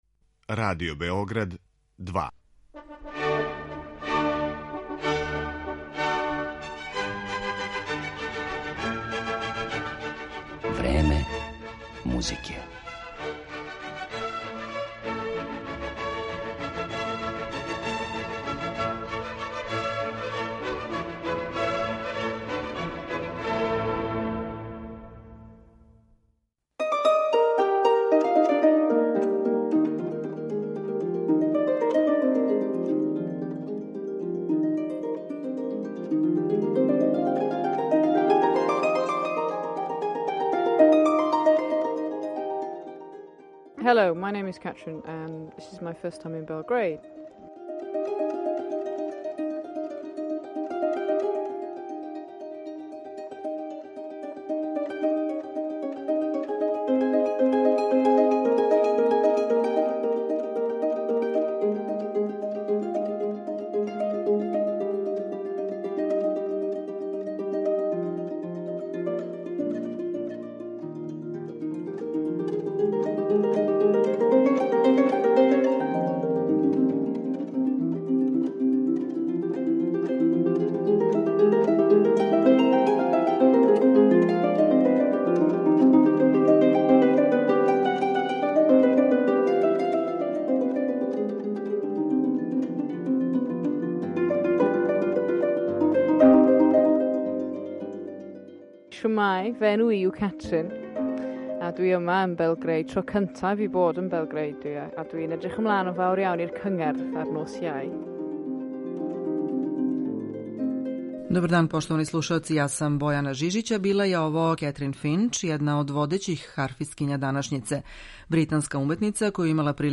као и кроз ексклузивни интервју.